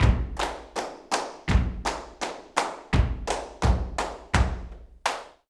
Index of /90_sSampleCDs/Best Service ProSamples vol.46 - Flamenco [AKAI] 1CD/Partition C/FARRUCA TAP